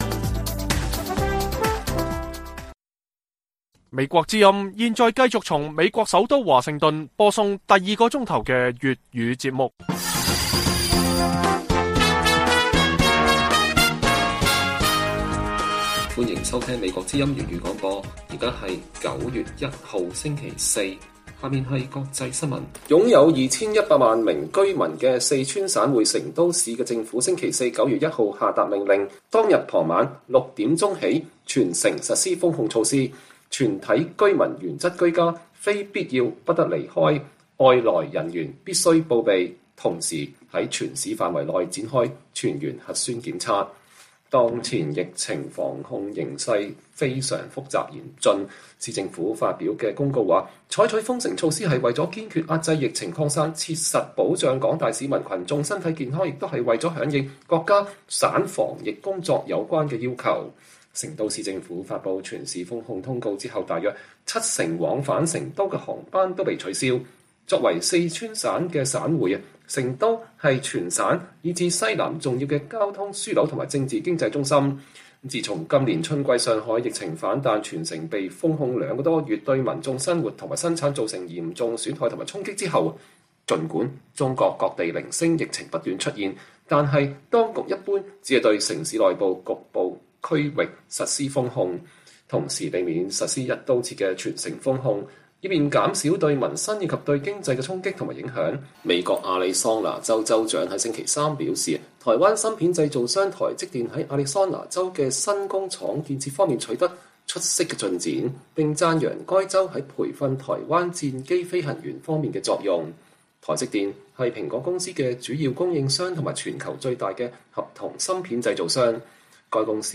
粵語新聞 晚上10-11點: 成都下令全城2100萬人禁足在家並全員核檢